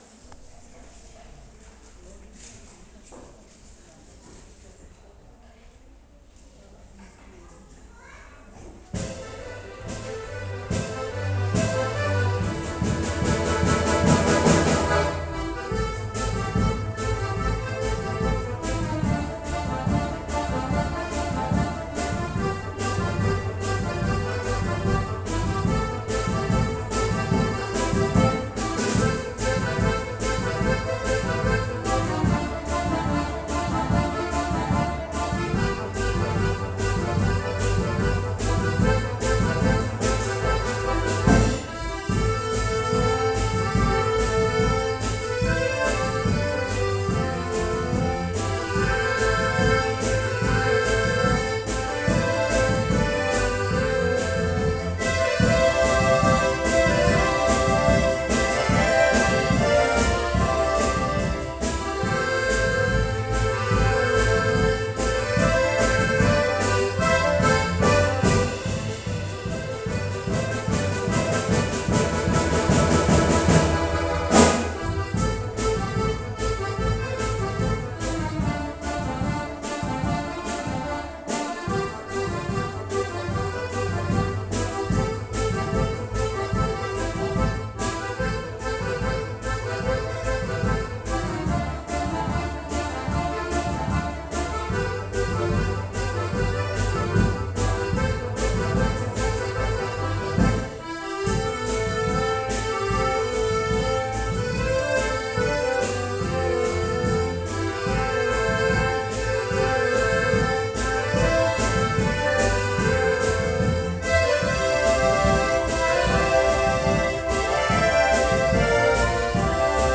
Konzerte in Graz, Wagrain, Feldkirchen, Kumberg, seit 2017
Vom Kinderorchester: